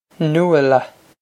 Nuala Noo-uh-luh
Pronunciation for how to say
Noo-uh-luh
This is an approximate phonetic pronunciation of the phrase.